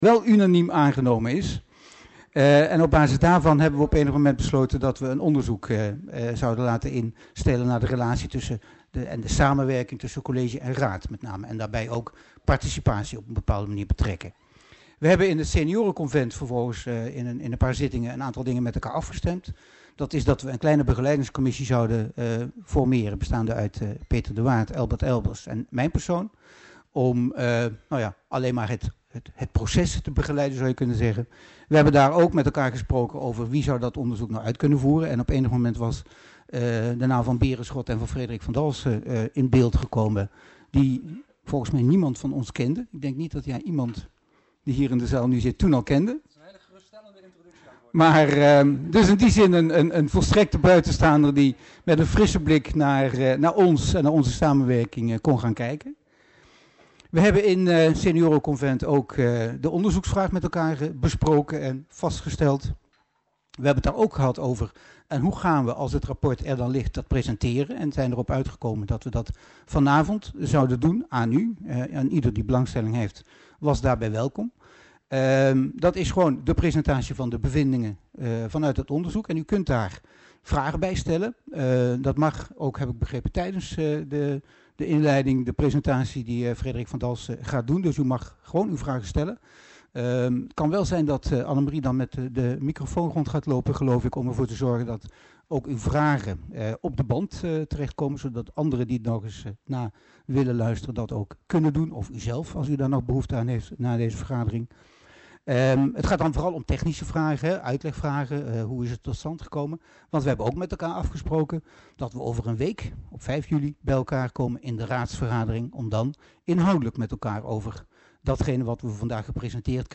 Locatie gemeentehuis Elst Voorzitter dhr. A.S.F. van Asseldonk Toelichting Informatiebijeenkomst / Presentatie rapport nav motie optimalisatie samenwerking raad en college Agenda documenten 18-06-28 Opname hal 1.